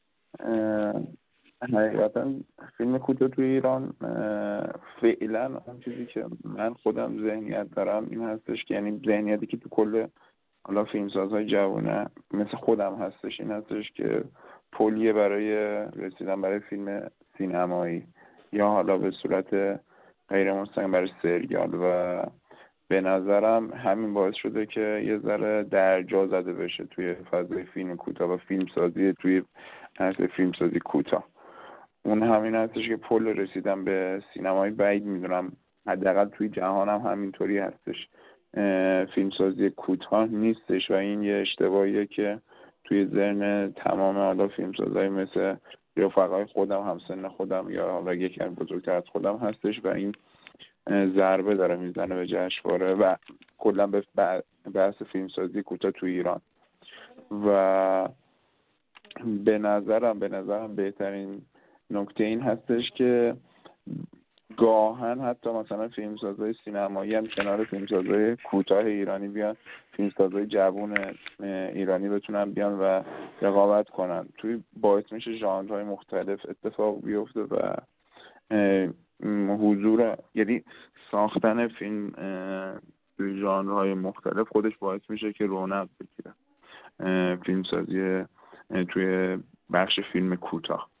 یک مستندساز: